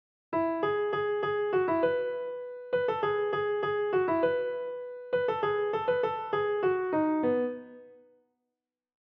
This has established a pattern in our minds, and unconsciously sets up our expectations that we’ll hear the pattern a third time.
By the end of those 8 bars we’ve heard the same thing twice.